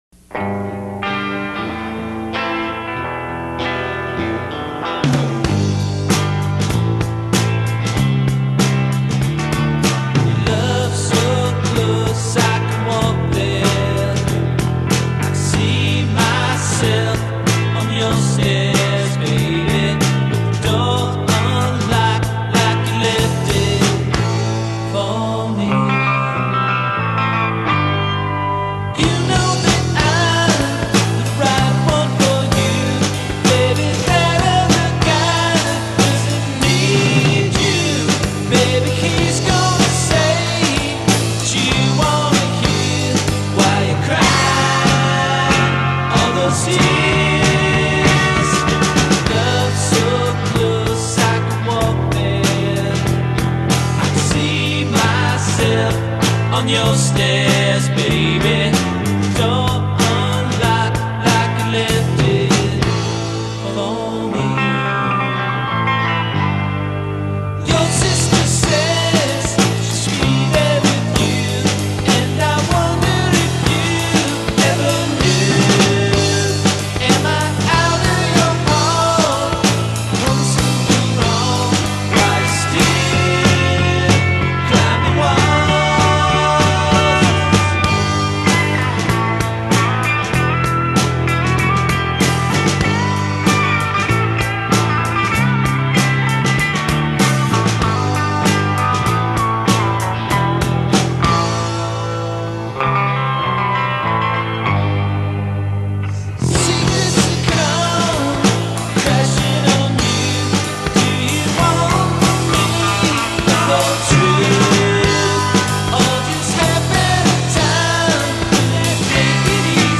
Twang it!